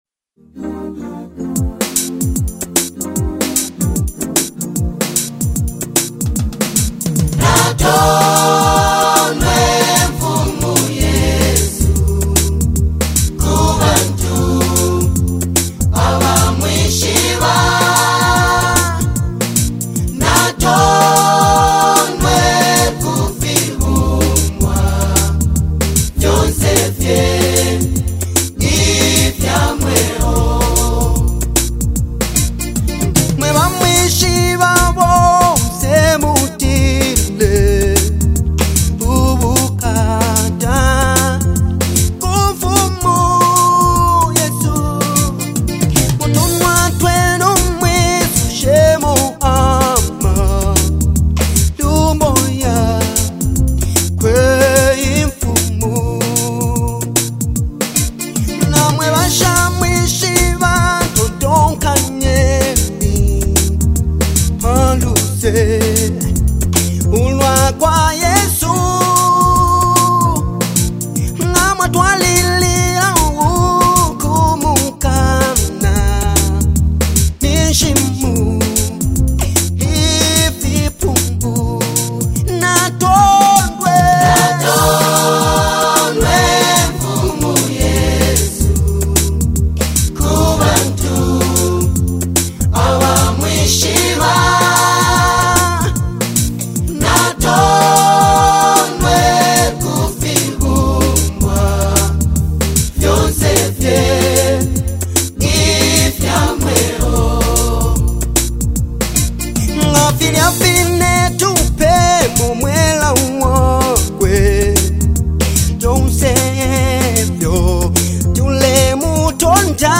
Zambia’s renowned gospel minister and award-winning artist
powerful voice and heartfelt delivery